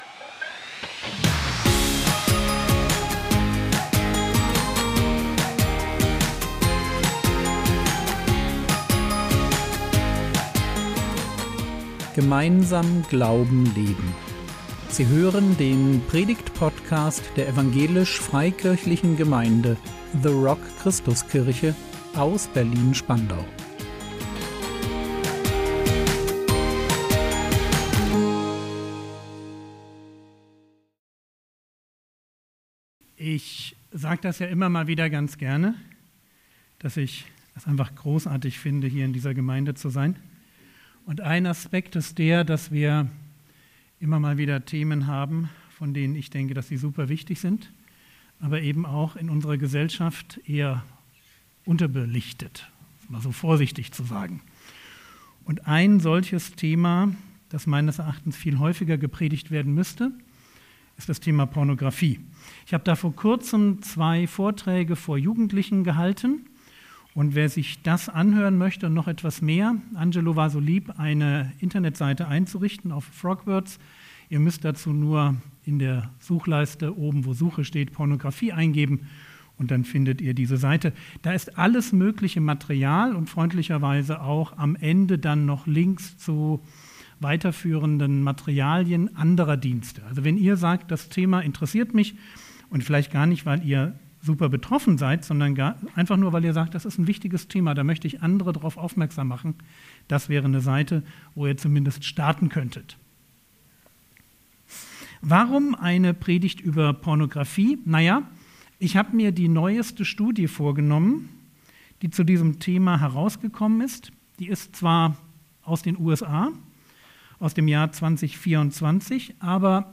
Was in der Dunkelheit wächst, zerstört im Licht | 19.01.2025 ~ Predigt Podcast der EFG The Rock Christuskirche Berlin Podcast